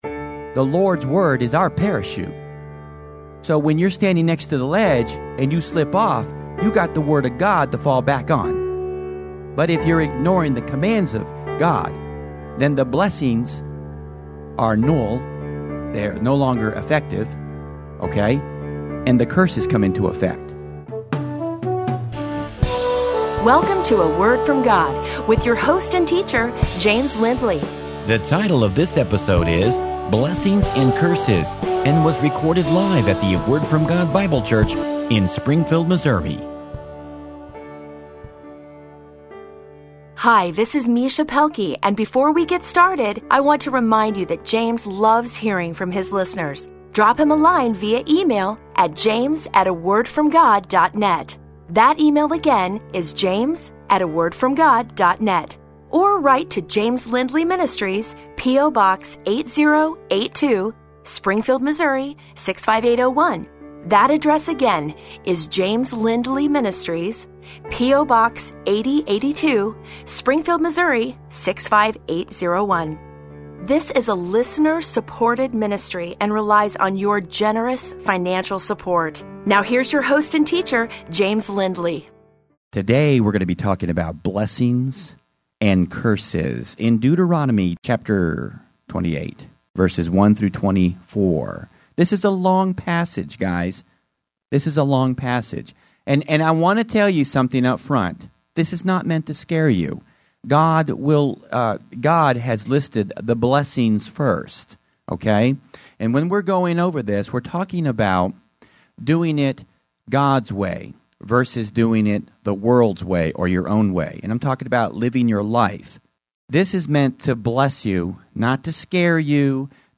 However, it is not my intention in this sermon to build fear in the believer’s life and in their view of God.